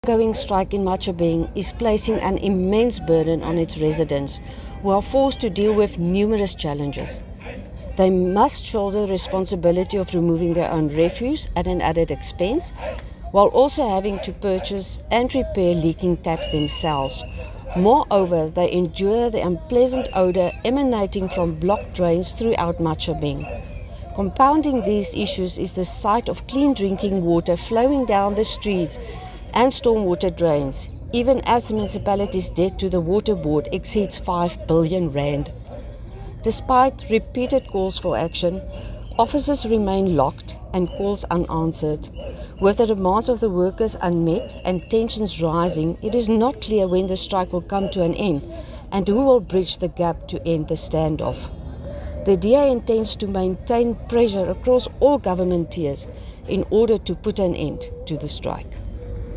Afrikaans soundbites by Cllr Maxie Badenhorst.